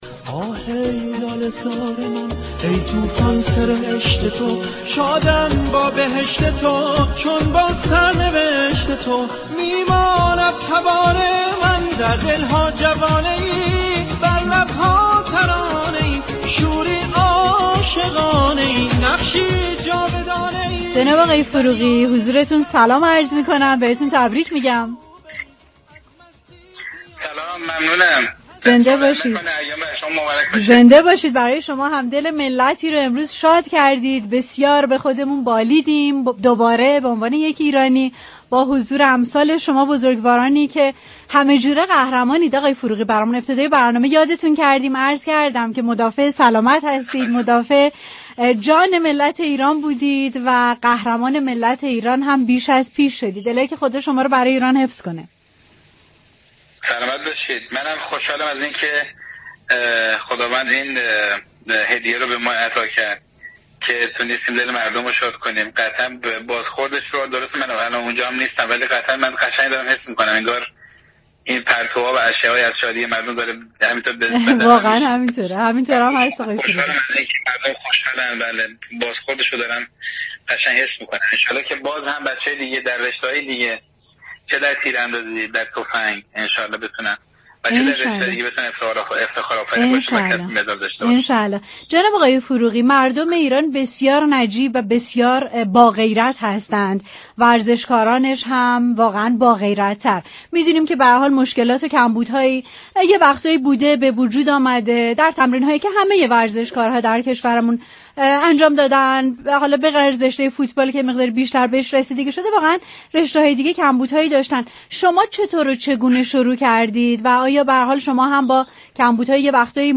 به گزارش پایگاه اطلاع رسانی رادیو تهران، جواد فروغی قهرمان رشته تیراندازی كشورمان با تپانچه بادی كه در رقابت های المپیك توكیو اولین مدال طلای كاروان المپیك ایران را از آن خود كرده است در گفتگو با برنامه تهران من رادیو تهران ضمن اظهار خرسندی از این هدیه الهی و خوشحالی مردم ایران گفت: بازخورد این شادی و خوشحالی را از دور حس می كنم و از خوشحالی هم میهنانم خوشحال هستم و امیدوارم دیگر عضوهای تیم ملی المپیك ایران هم مدال كسب كنند.